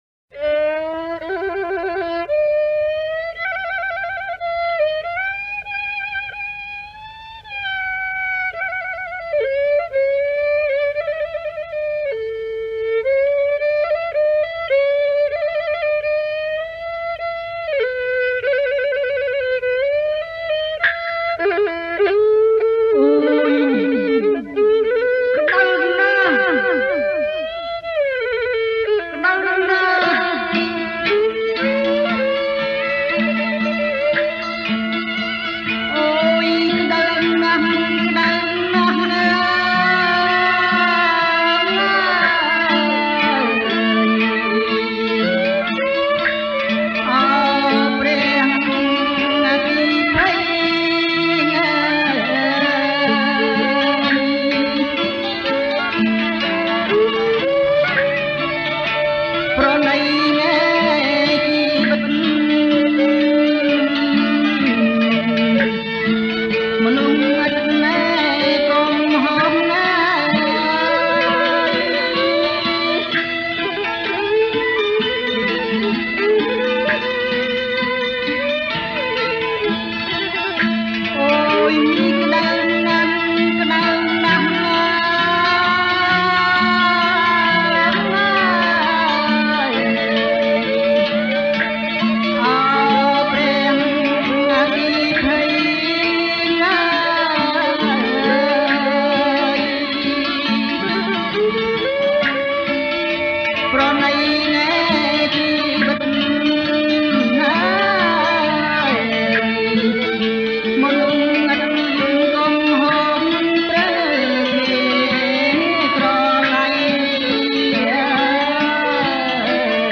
ប្រគំជាចង្វាក់ បាសាក់